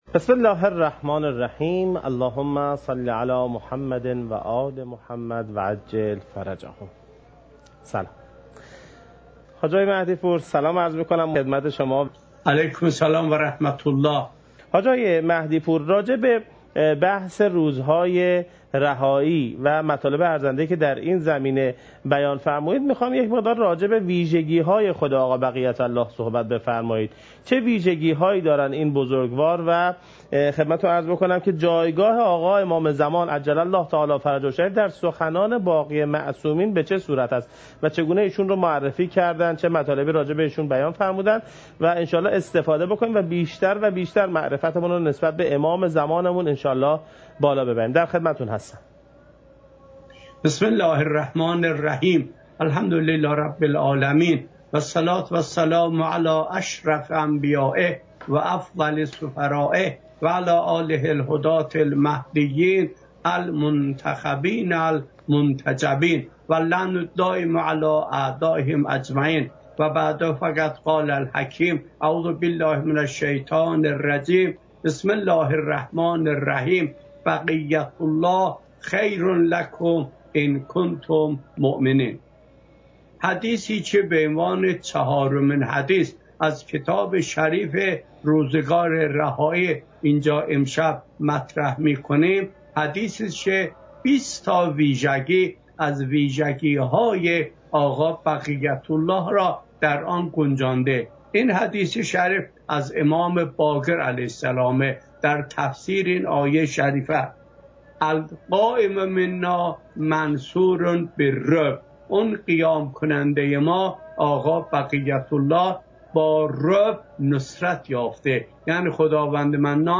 حجم: 8.26 MB | زمان: 34:46 | تاریخ: 1441هـ.ق | مکان: کربلا